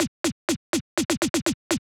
123 BPM Beat Loops Download